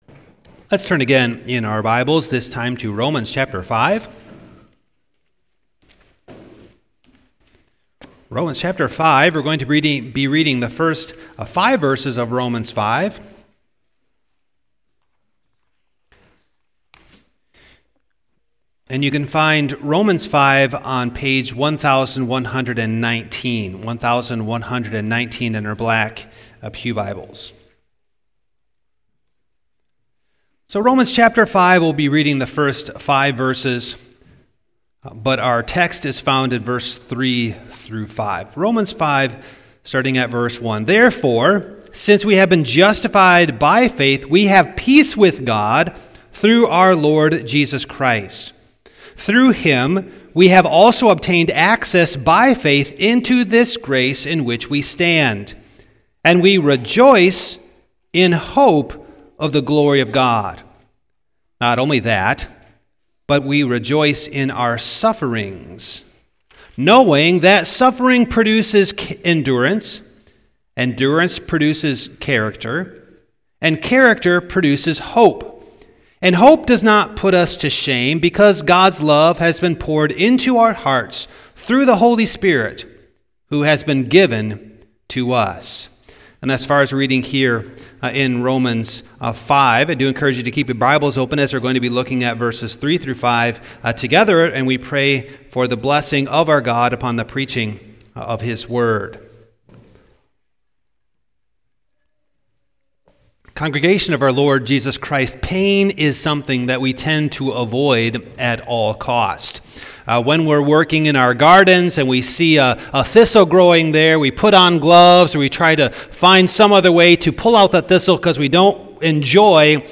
Passage: Romans 5:1-5 Service Type: Morning Download Files Notes « From Exclusion to Embrace The 8th Commandment